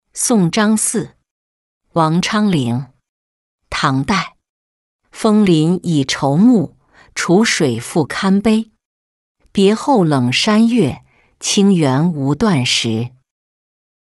送张四-音频朗读